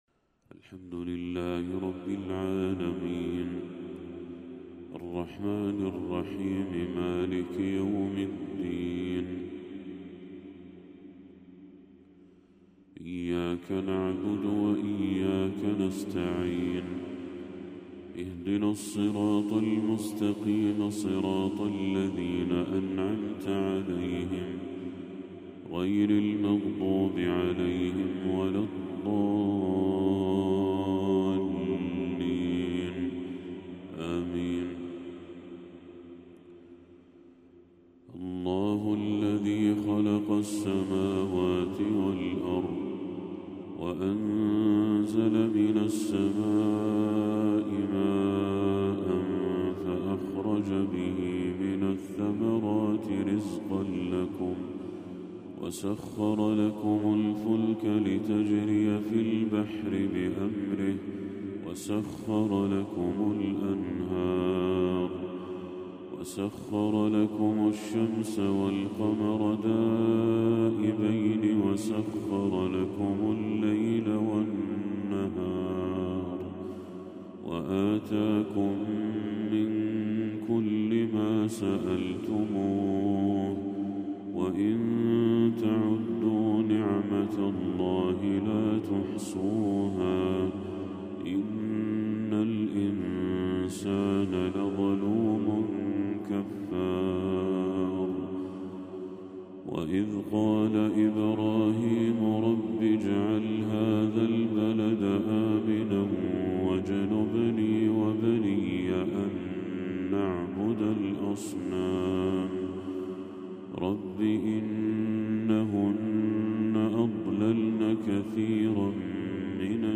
تلاوة عذبة بأداء هادئ لخواتيم سورة إبراهيم للشيخ بدر التركي | فجر 24 ربيع الأول 1446هـ > 1446هـ > تلاوات الشيخ بدر التركي > المزيد - تلاوات الحرمين